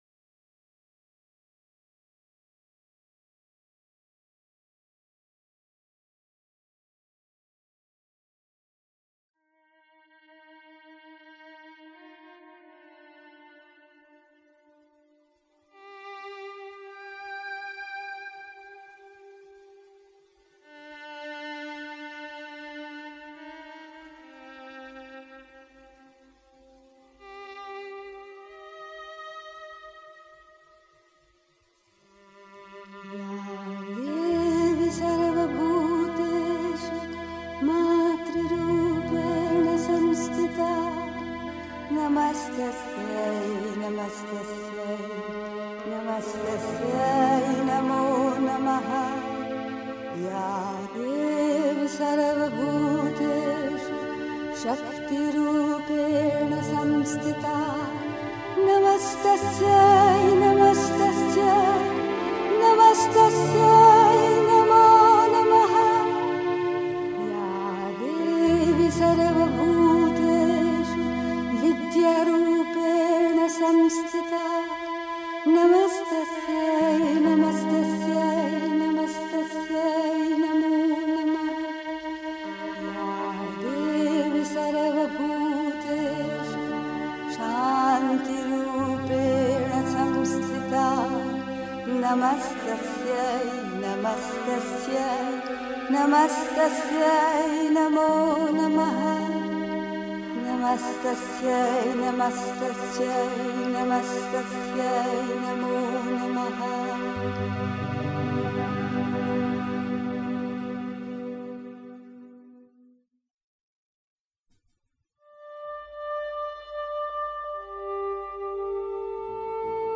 Die Befreiung der Seele fühlen (Die Mutter, White Roses, 23 June 1963) 3. Zwölf Minuten Stille.